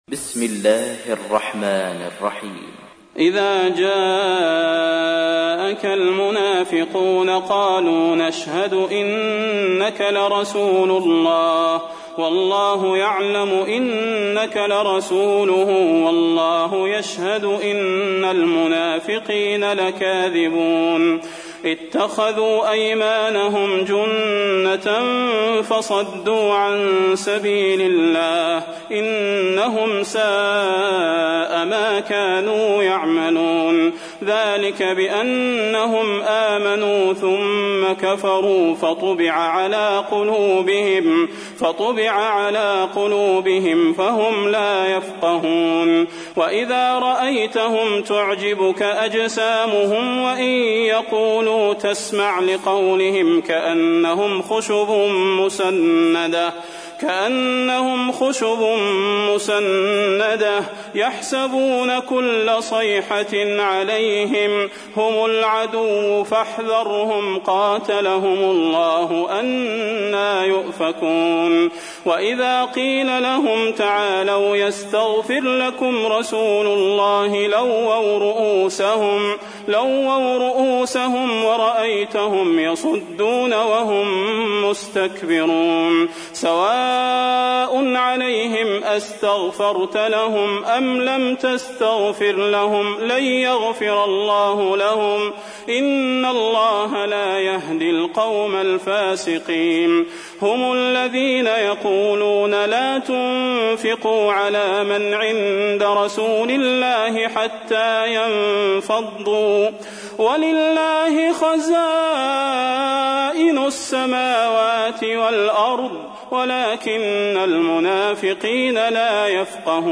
تحميل : 63. سورة المنافقون / القارئ صلاح البدير / القرآن الكريم / موقع يا حسين